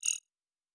Digital Click 02.wav